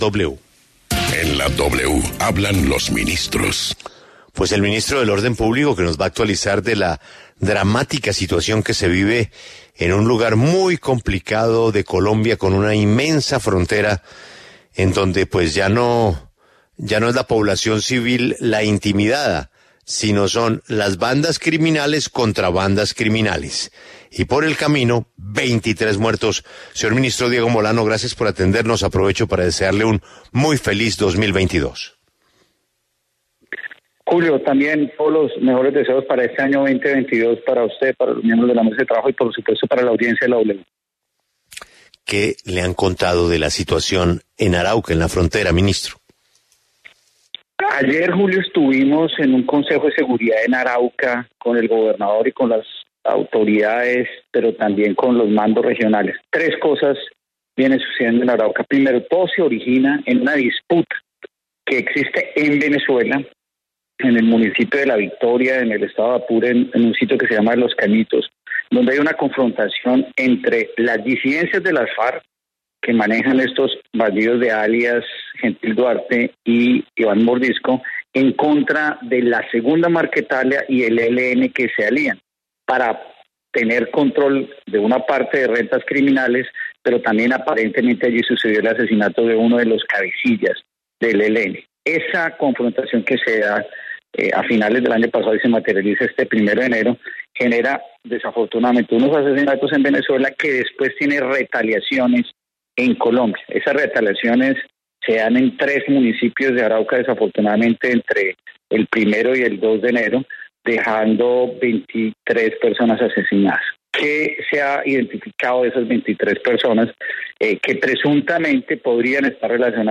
En La W, el ministro de Defensa, Diego Molano, se refirió en La W a la grave situación de orden público que vive el departamento de Arauca y a la acción de la fuerza pública.